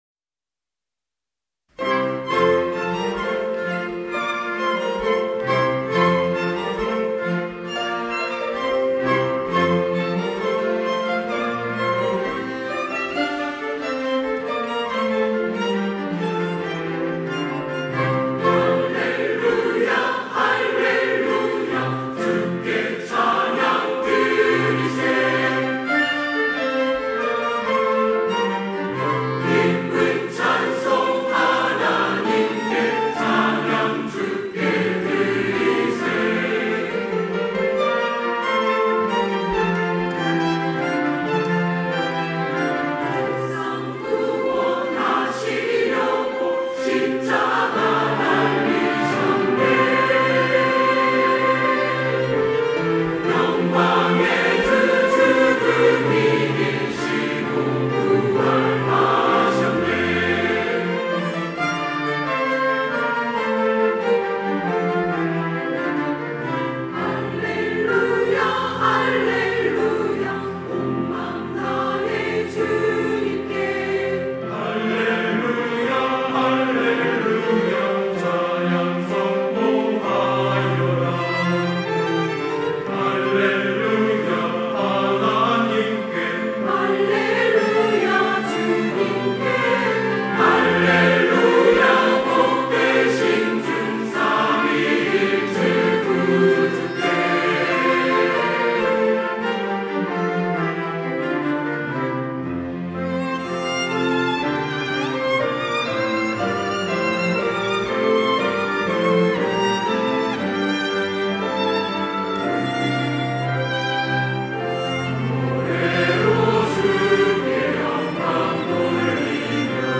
호산나(주일3부) - 승리의 할렐루야
찬양대 호산나